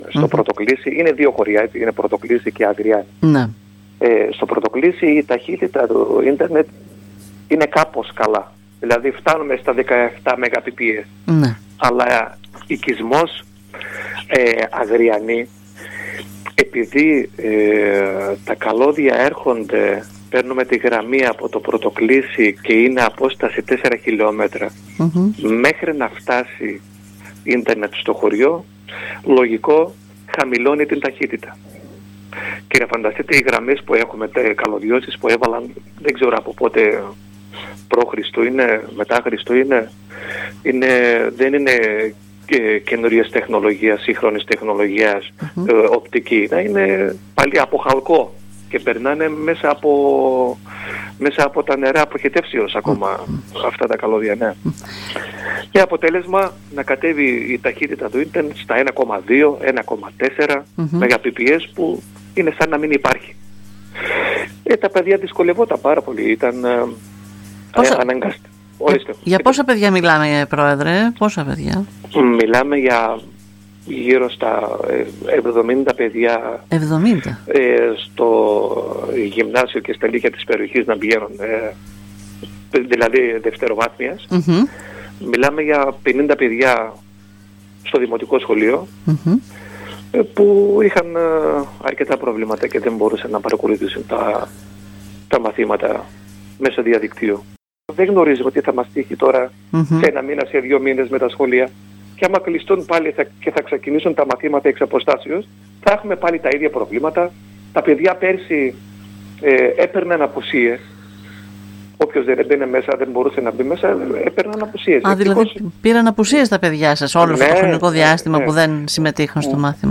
Ο πρόεδρος του χωριού Αλί Σαλίογλου νέος και  δραστήριος με όρεξη να προσφέρει, κατέθεσε σήμερα την αγωνία και τον προβληματισμό του, μιλώντας στην ΕΡΤ Ορεστιάδας.
Αλί-Σαλίογλου-πρόεδρος-Αγριάνης.mp3